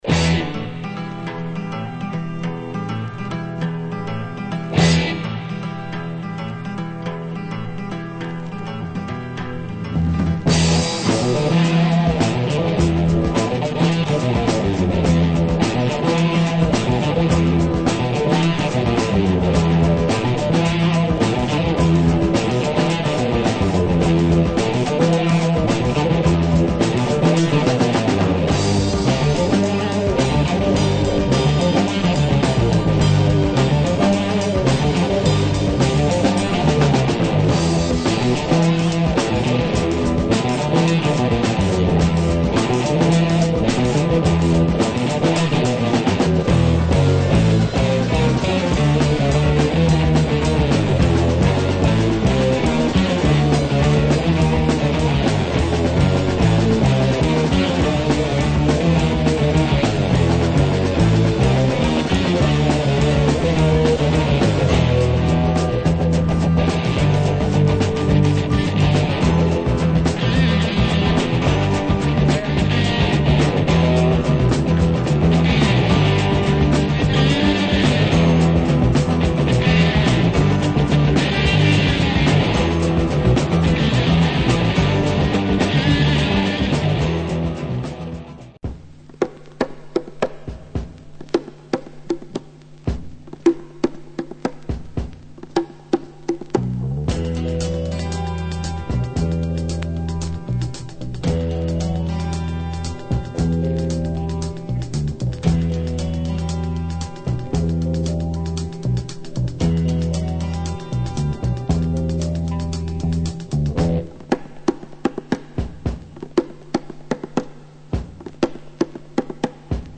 Italian psych, prog groove and exotic drama beats !